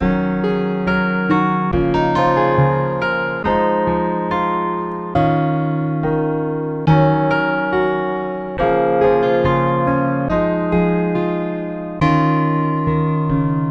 寒冷的吉他
描述：有点像Gunna的节奏
标签： 140 bpm Hip Hop Loops Guitar Acoustic Loops 2.31 MB wav Key : D FL Studio
声道立体声